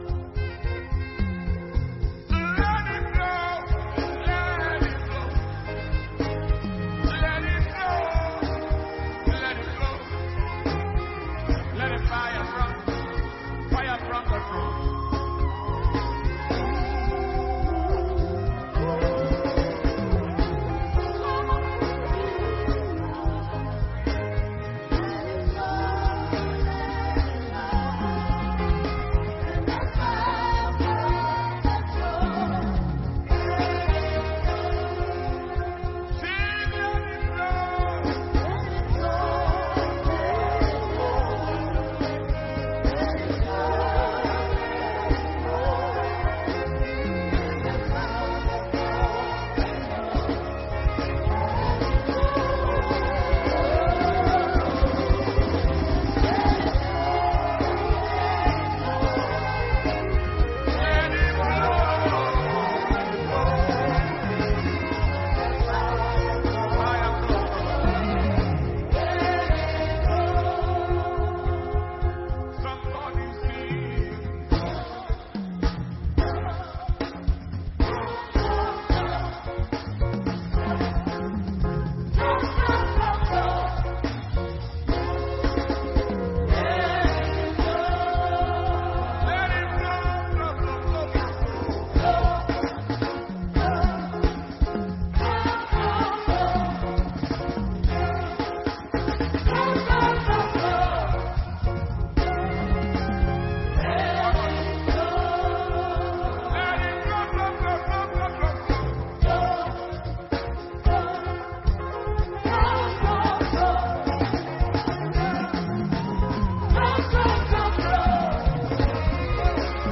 June 2023 Anointing Service – Sunday June 11th, 2023